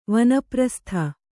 ♪ vana prastha